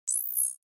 جلوه های صوتی
دانلود صدای ربات 53 از ساعد نیوز با لینک مستقیم و کیفیت بالا